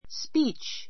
speech 中 A1 spíːtʃ ス ピ ー チ 名詞 ❶ 演説 , スピーチ, 挨拶 あいさつ ⦣ つづり字に注意.